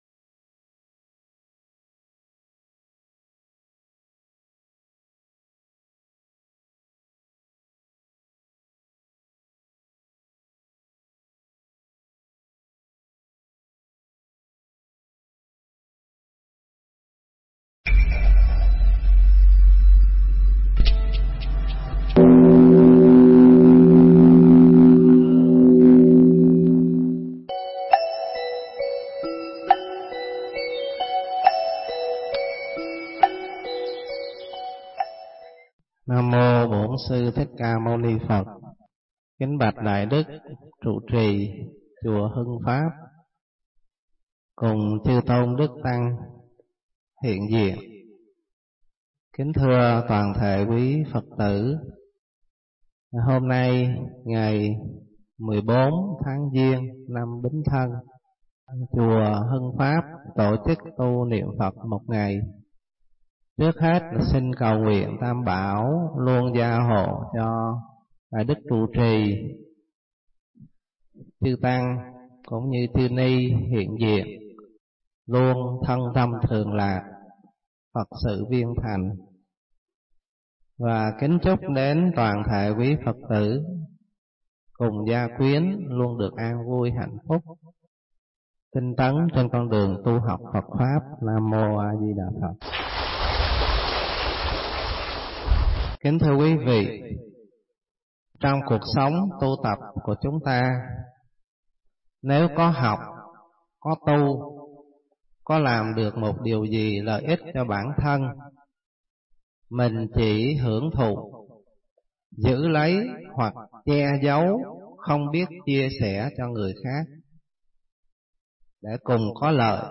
Nghe Mp3 thuyết pháp Tự Lợi Lợi Tha